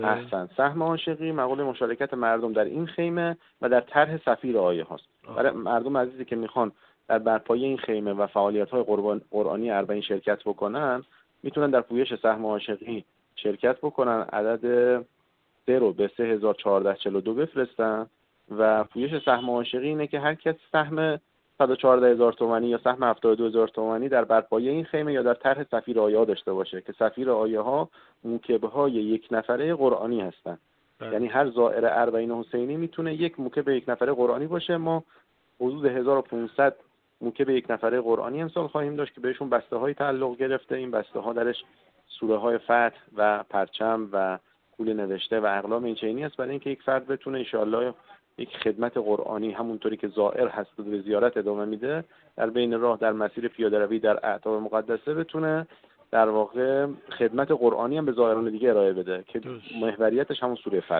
در گفت‌و‌گو با خبرنگار ایکنا